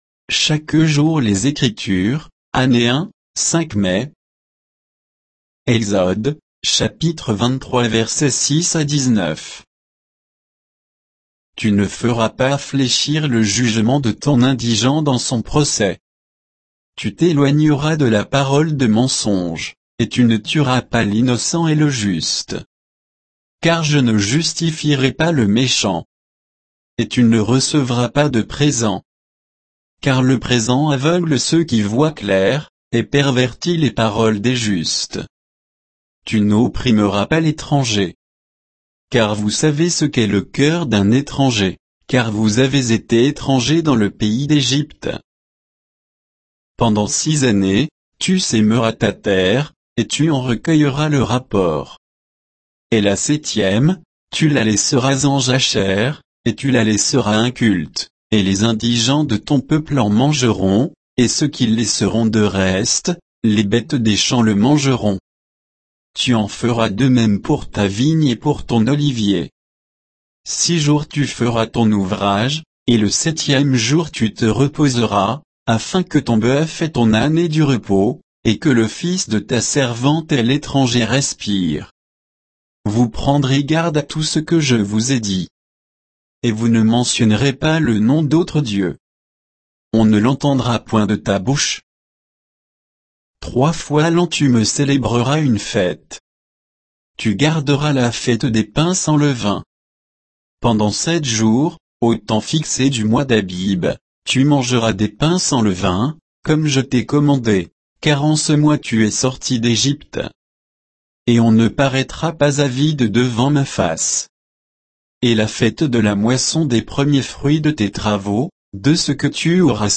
Méditation quoditienne de Chaque jour les Écritures sur Exode 23, 6 à 19